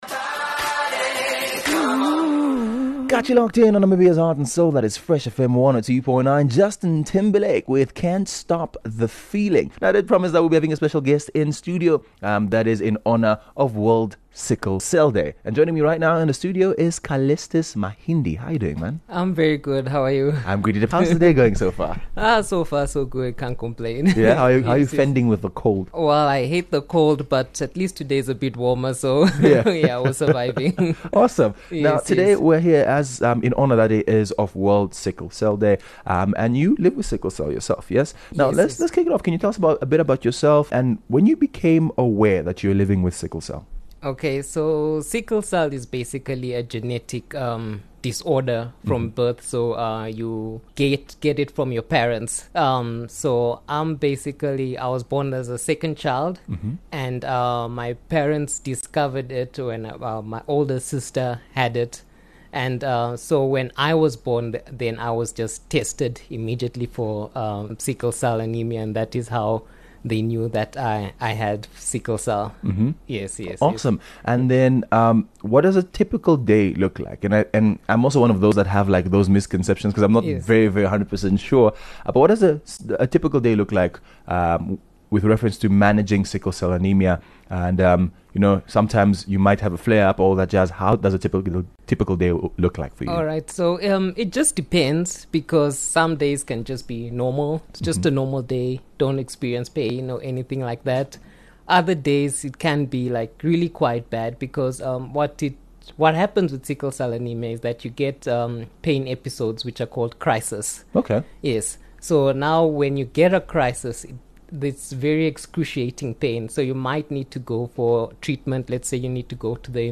Don’t miss this heartfelt and informative discussion.